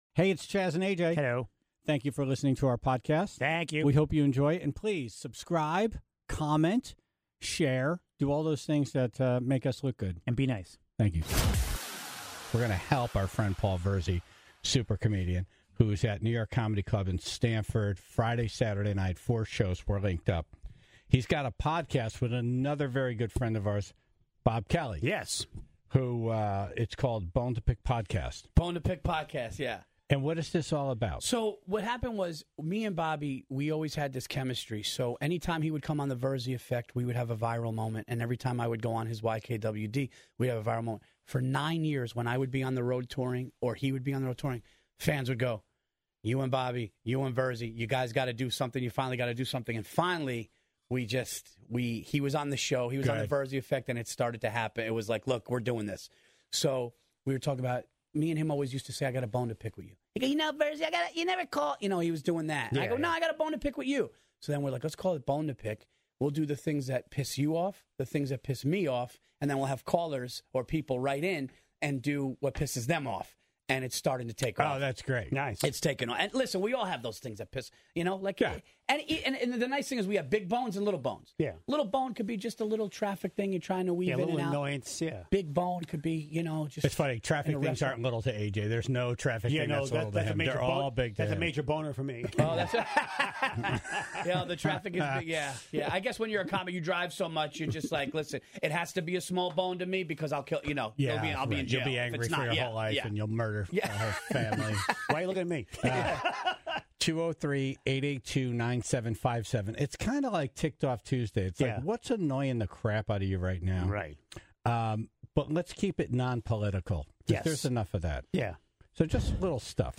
More calls from the Tribe about what drives them crazy, including a lot of elevator etiquette.